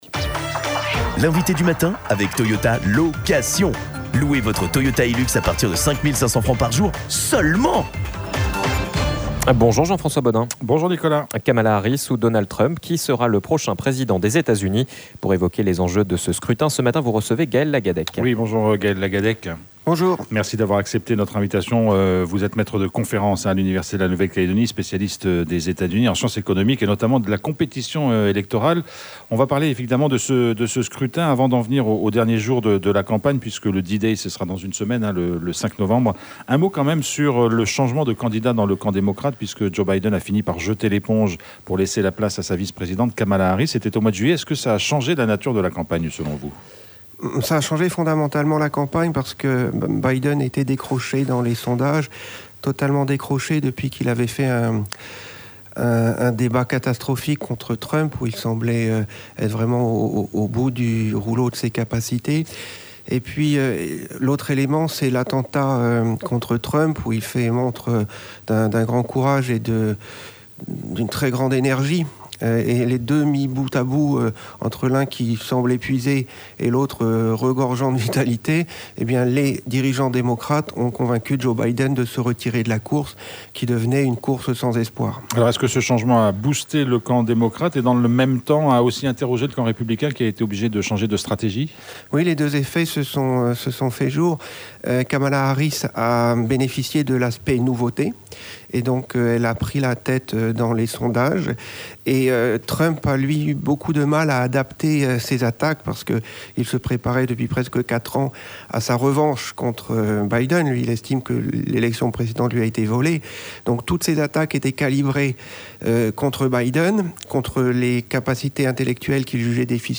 Sur quoi va se jouer ce scrutin ? et quels en sont les enjeux ? Nous en avons parlé ce matin avec notre invité.